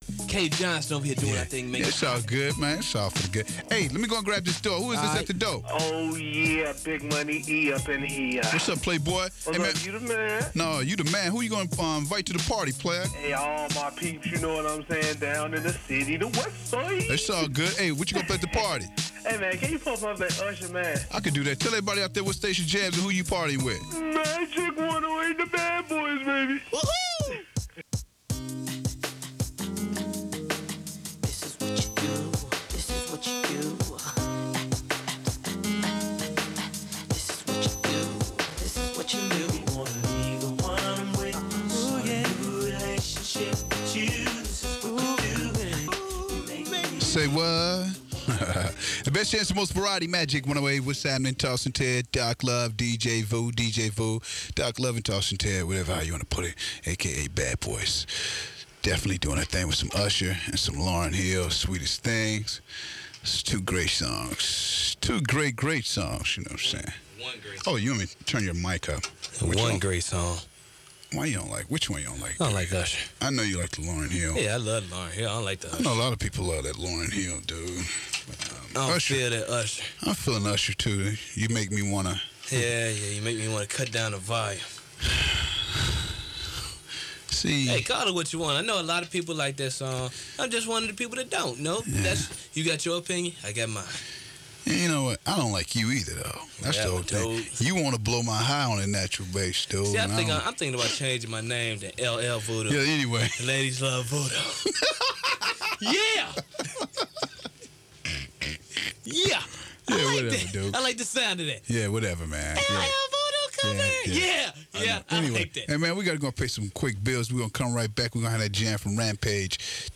KMJM Bad Boyz Aircheck · St. Louis Media History Archive